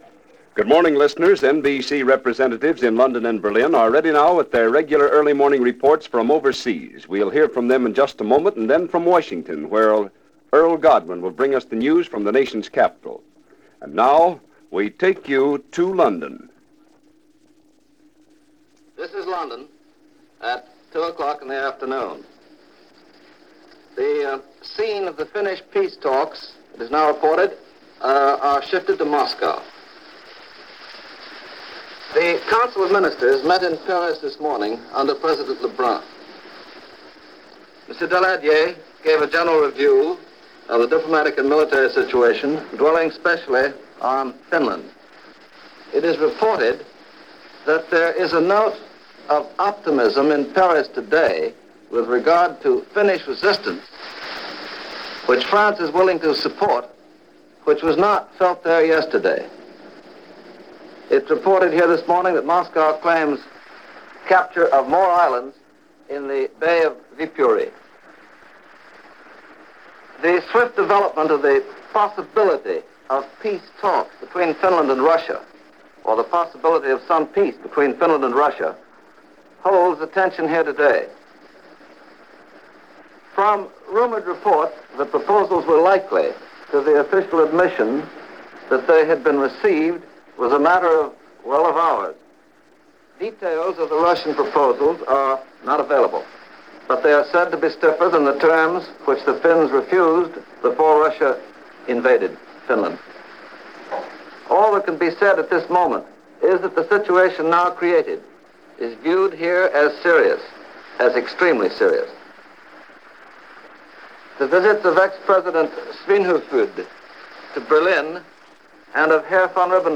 March 9, 1940 - Russo-Finnish War- Peace Overtures - 1940 Presidential Election - The Hatch Act - news for this day in 1940.